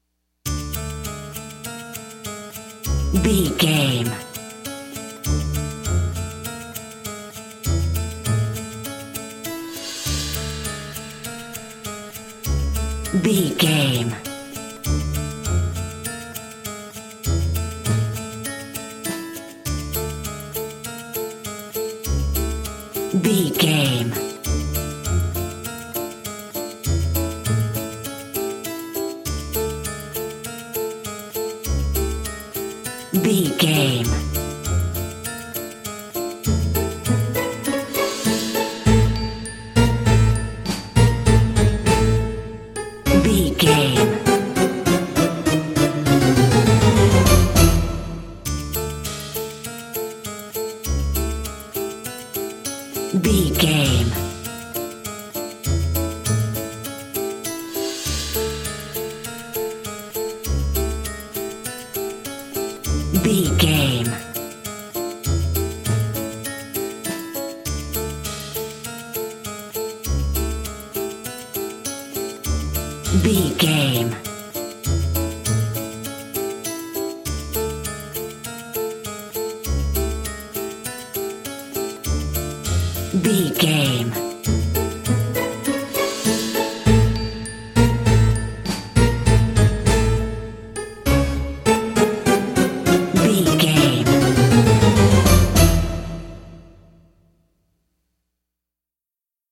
Aeolian/Minor
orchestra
harpsichord
silly
circus
goofy
comical
cheerful
perky
Light hearted
quirky